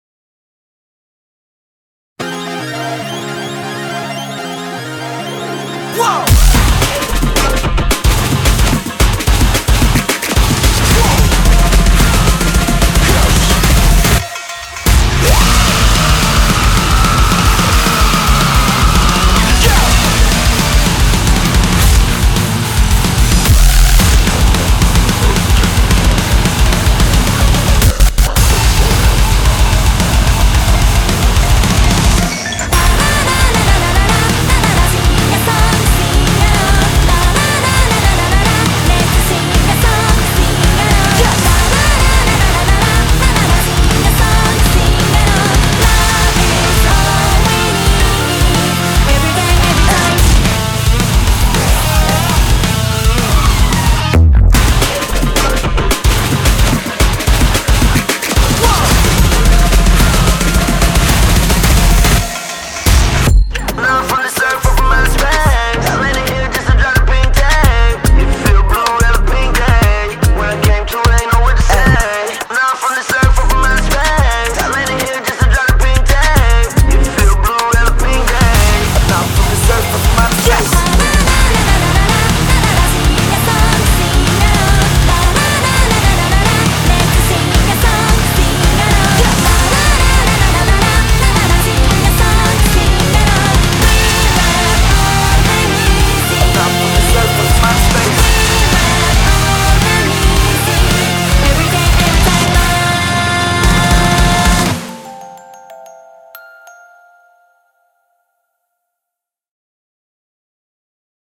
BPM110-440
Audio QualityCut From Video